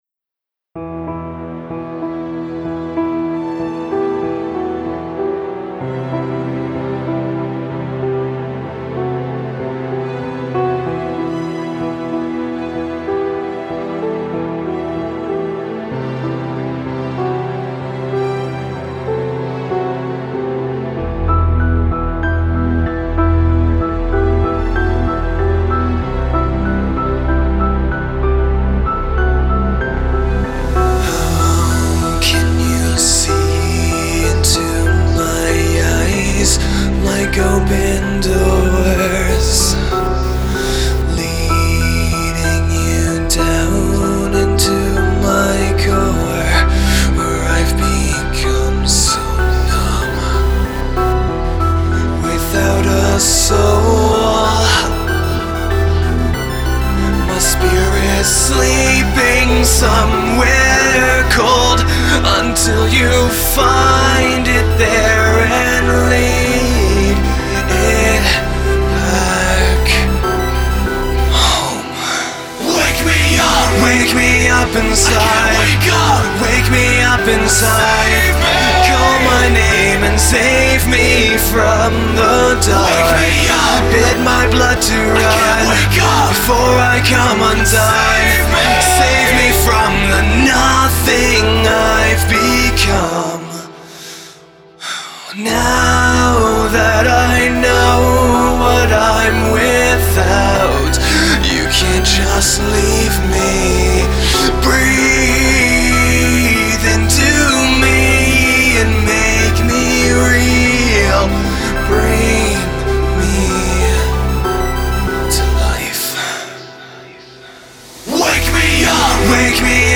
Just a quick little cover